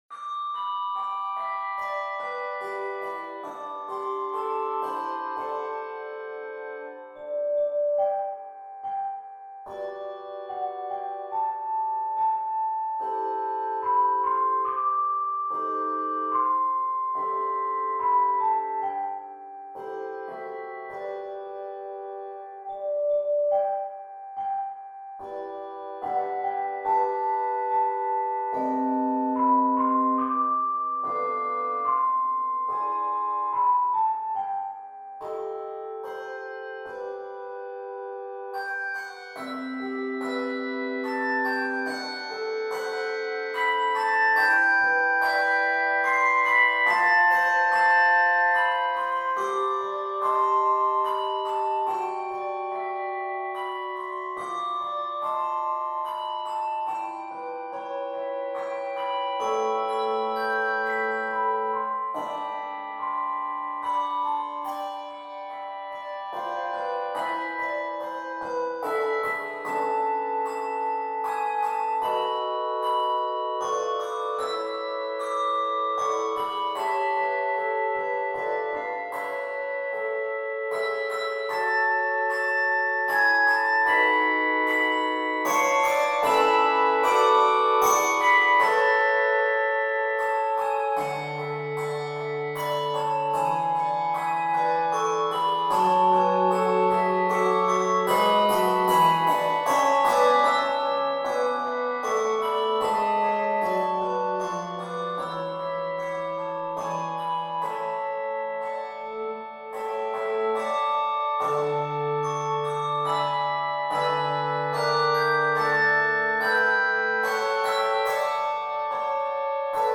Keys of G Major, A Major, and D Major.